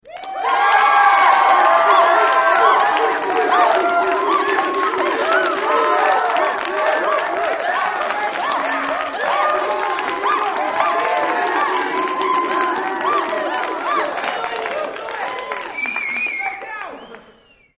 Cheer